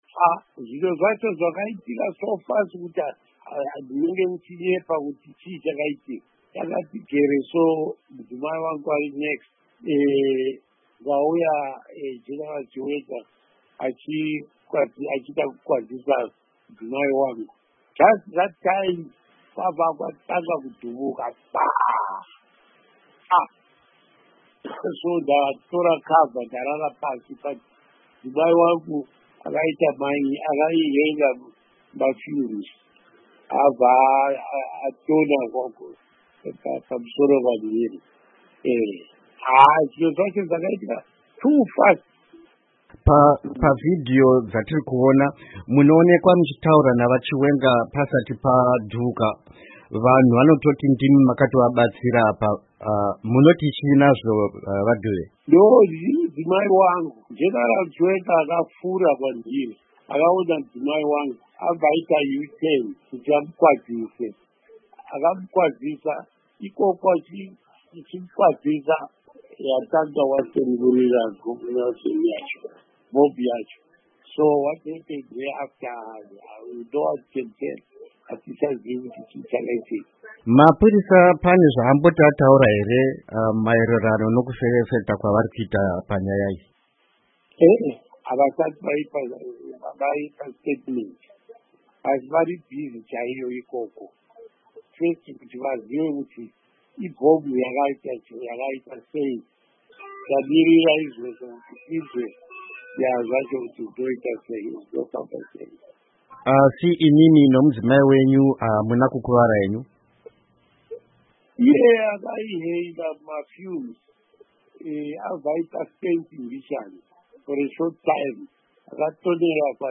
Hurukuro NaVaTshinga Dube